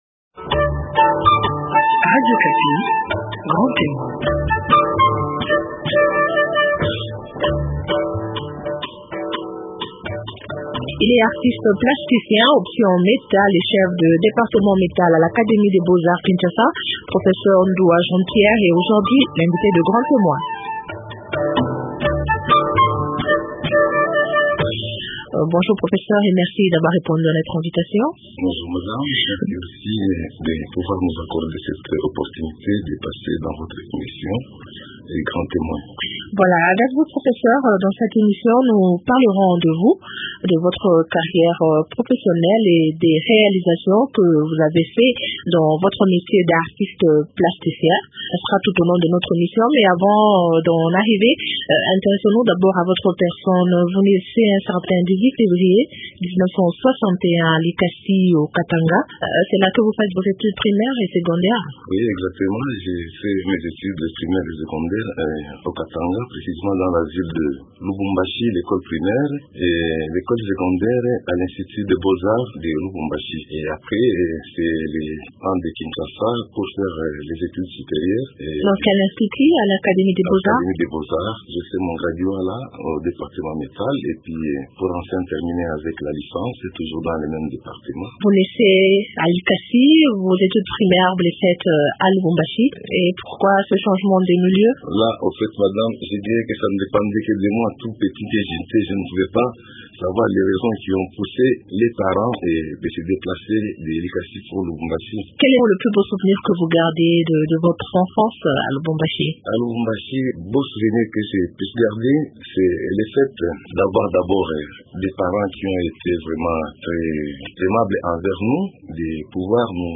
Découvrez aussi dans cet entretien sa passion pour la musique ancienne.